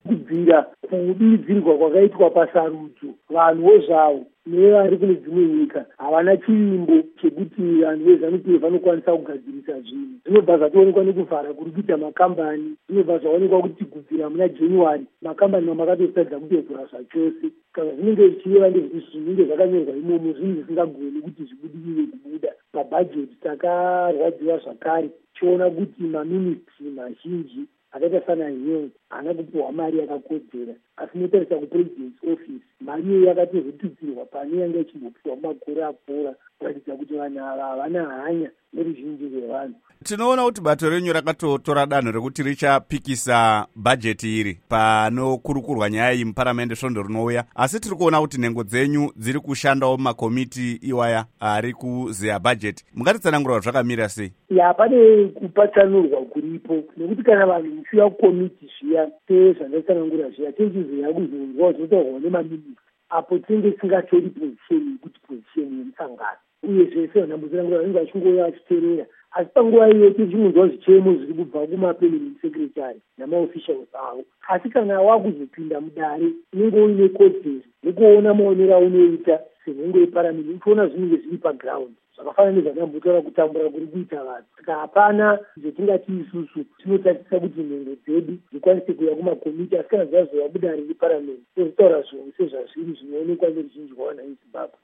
Hurukuro naVaInnocent Gonese